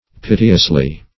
[1913 Webster] -- Pit"e*ous*ly, adv.